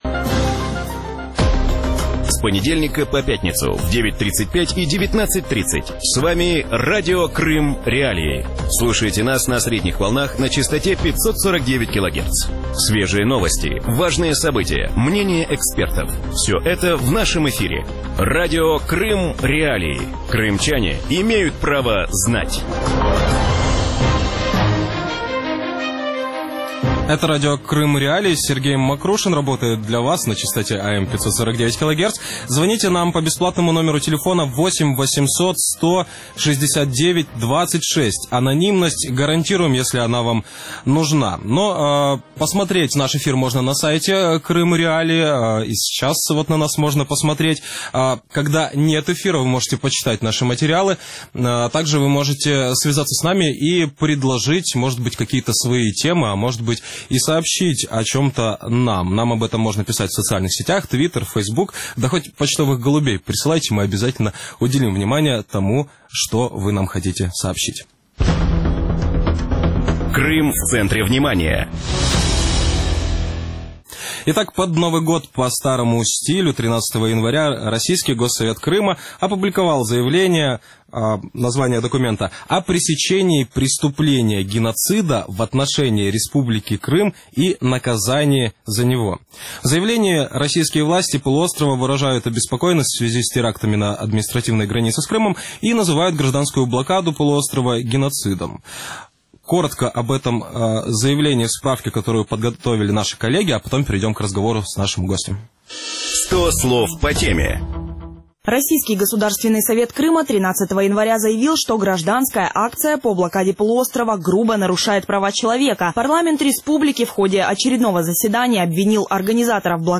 В вечернем эфире Радио Крым.Реалии обсуждают заявление российского Госсовета Крыма о том, что «гражданская блокада» полуострова стала геноцидом крымчан.